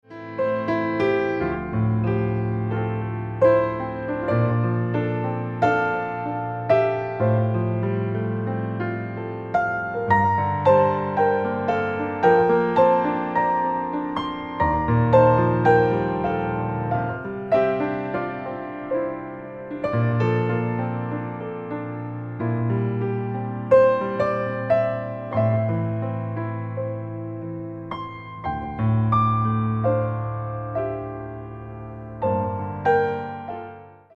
a series of instrumental recordings